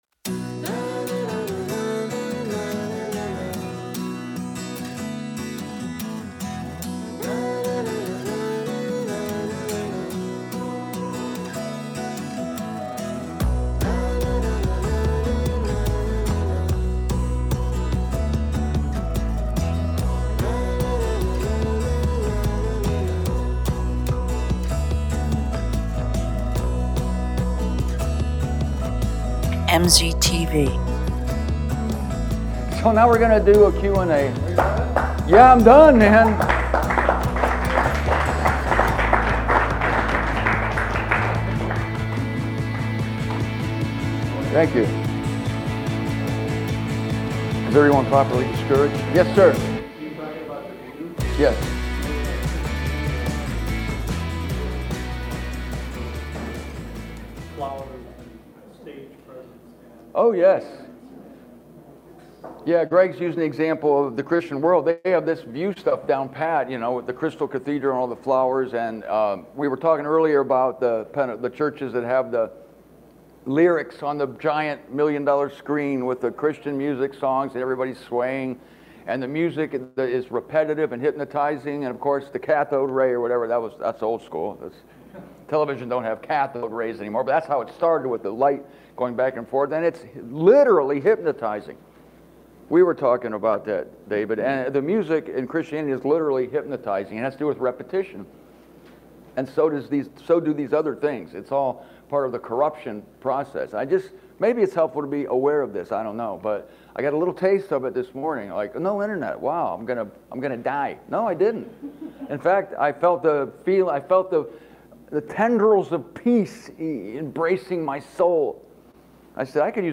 Then what is happening with people who report lights and tunnels? In this video, you will hear from people at the Nebraska conference who have been near death themselves.